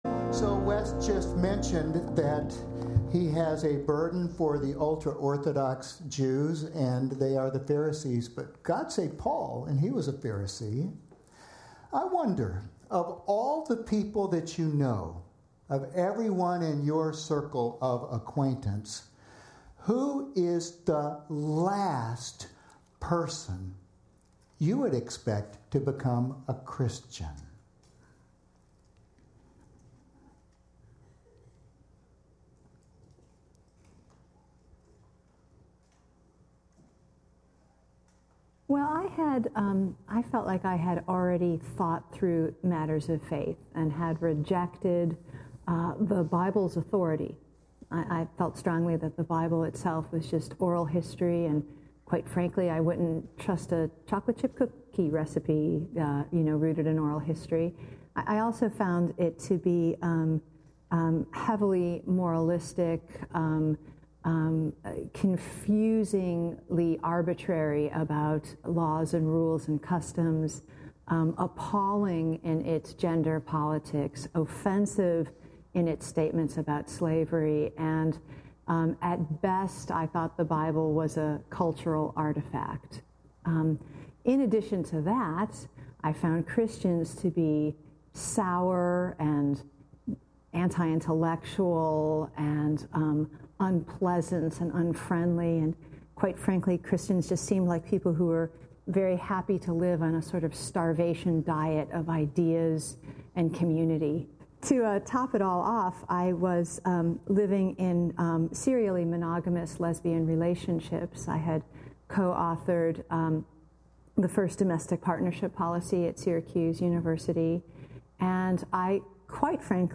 The Whole Story Passage: 2 Kings 5 Service Type: Sunday Morning Topics